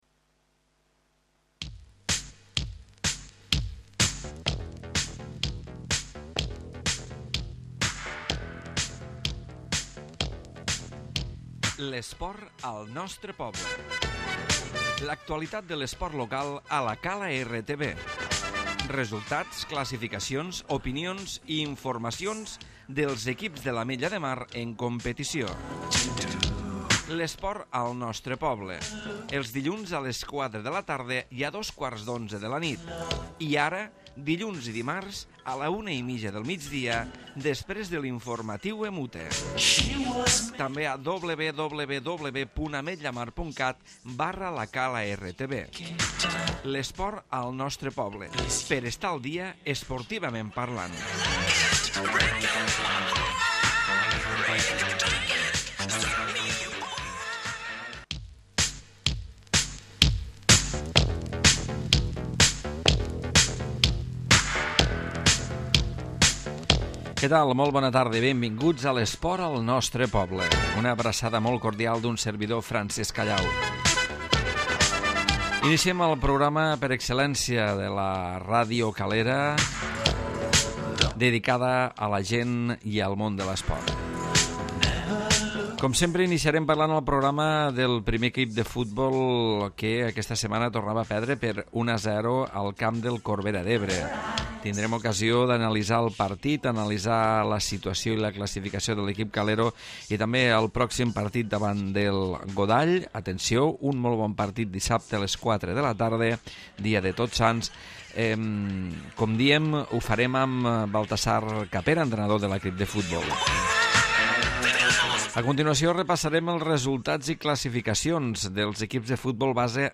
Programa d'actualitat esportiva.